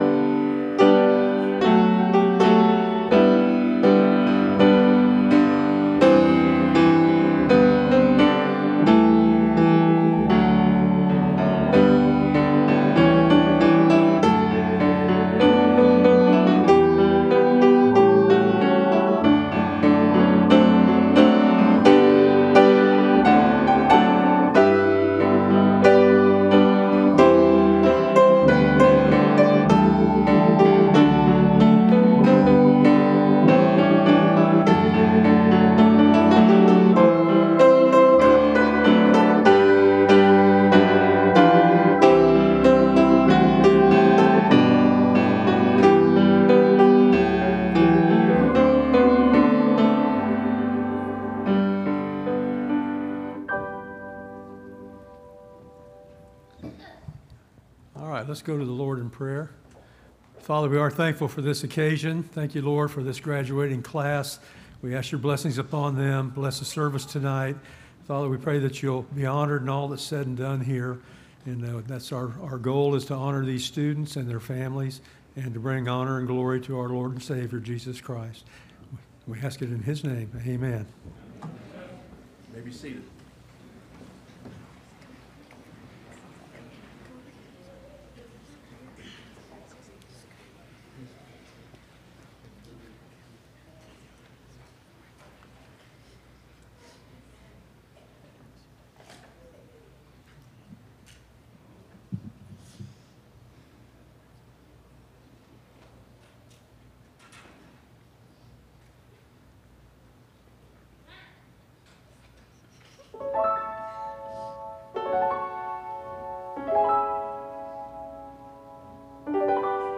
LCS High School Graduation/ “The Will Of God” – Landmark Baptist Church
Service Type: Wednesday